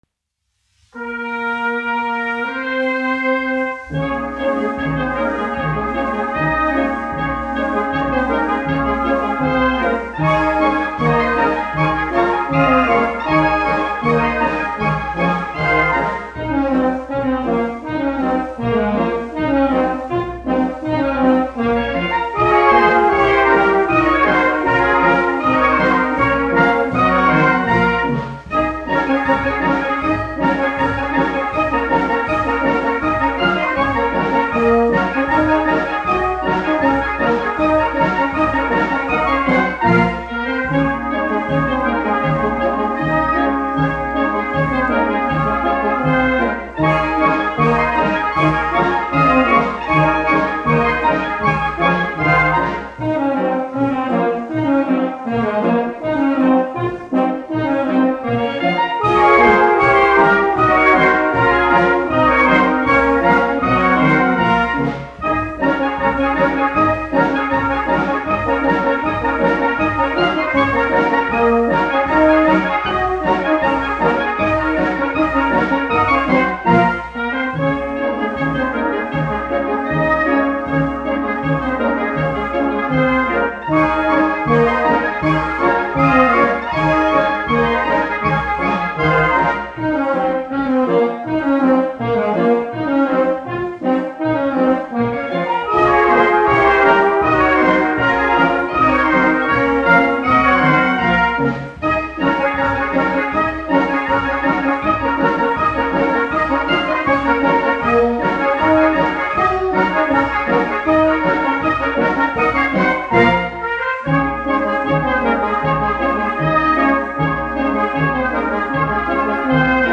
Garais dancis : latviešu tautas deja
1 skpl. : analogs, 78 apgr/min, mono ; 25 cm
Pūtēju orķestra mūzika, aranžējumi
Latvijas vēsturiskie šellaka skaņuplašu ieraksti (Kolekcija)